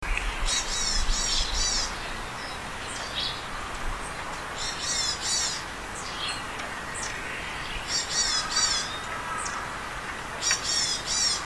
エナガ科 エナガ
庭園渓谷の楓の梢で、
鳴き声(179KB)
enaga.mp3